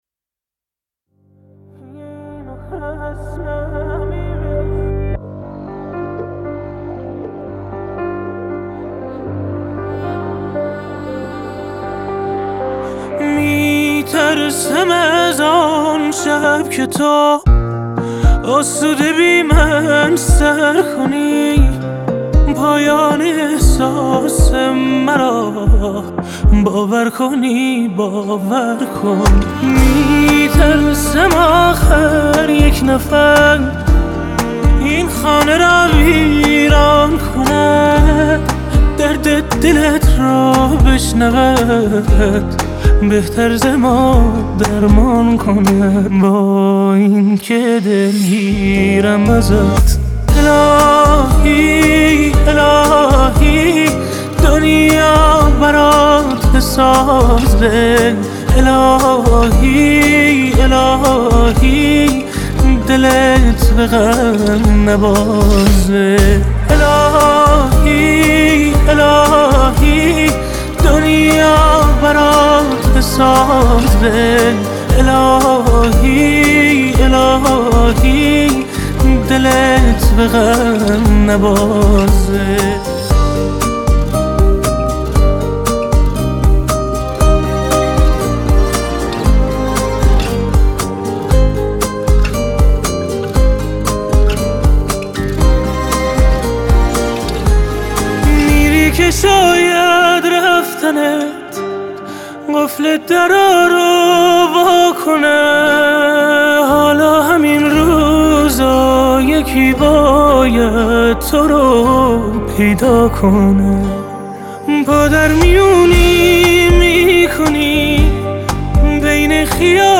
با صدای زیبا و دلنشین
آهنگ احساسی